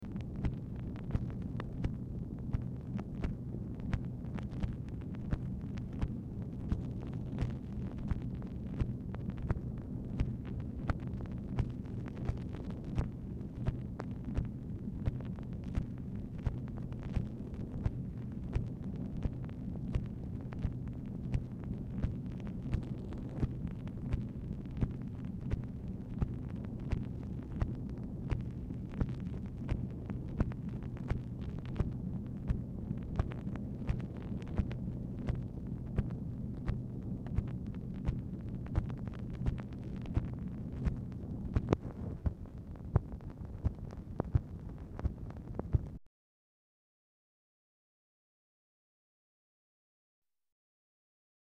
Telephone conversation # 6619, sound recording, MACHINE NOISE, 12/28/1964, time unknown | Discover LBJ
Telephone conversation
Format Dictation belt
LBJ Ranch, near Stonewall, Texas